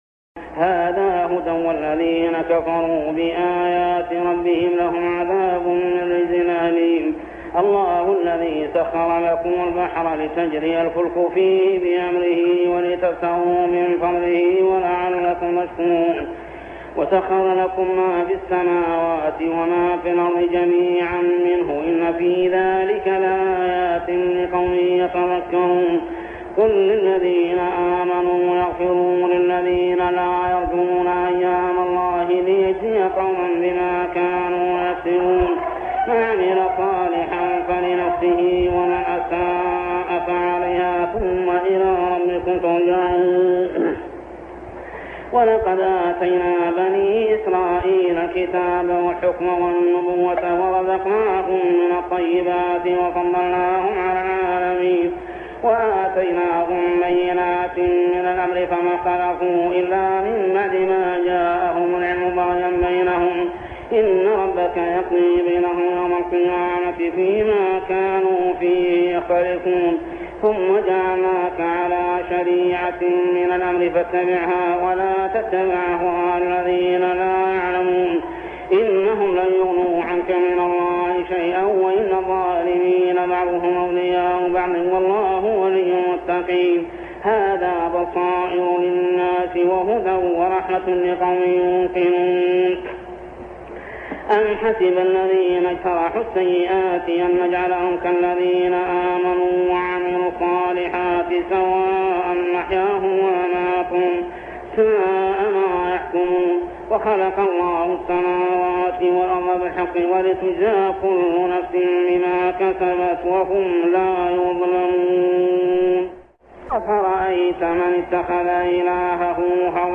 صلاة التراويح عام 1403هـ من سورة الجاثية 11-37 حتى سورة محمد كاملة | Tarawih prayer From Surah Al-Jathiyah to Surah Muhammad > تراويح الحرم المكي عام 1403 🕋 > التراويح - تلاوات الحرمين